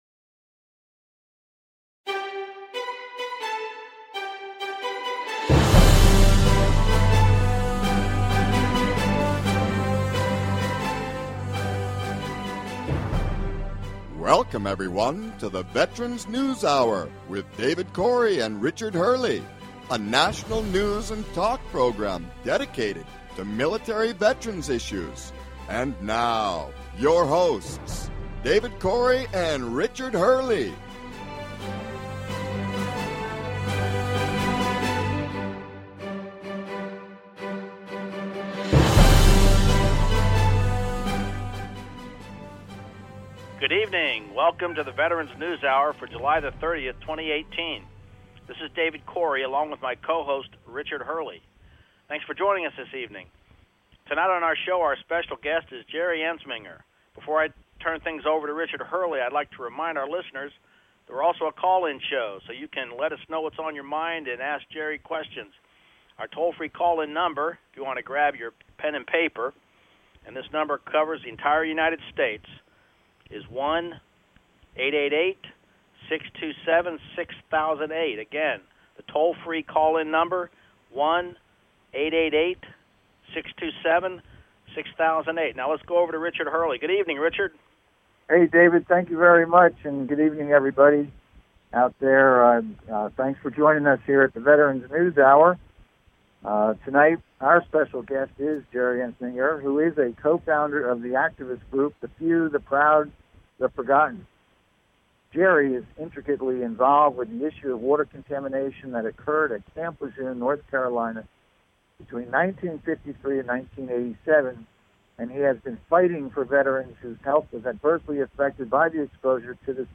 The Veterans News Hour